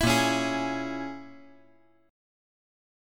A#m7b5 Chord
Listen to A#m7b5 strummed